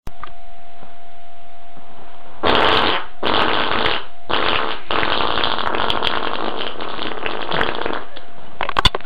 Huge Fart Sound Button - Free Download & Play